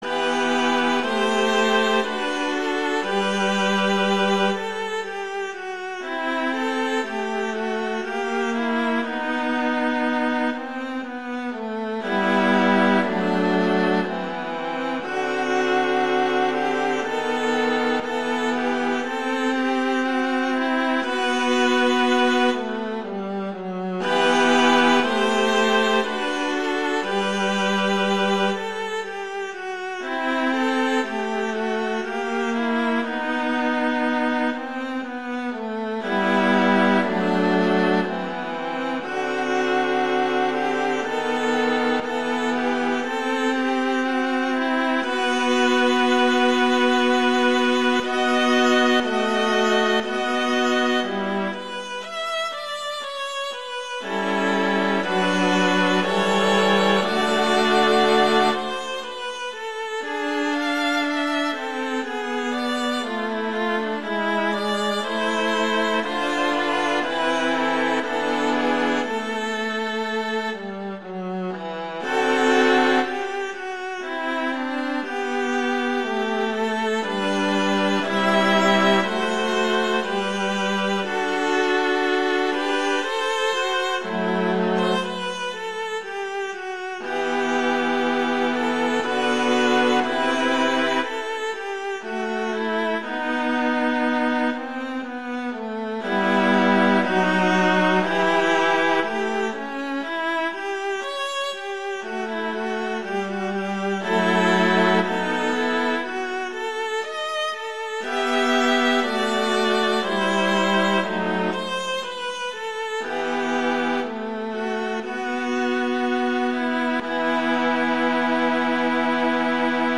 viola solo
classical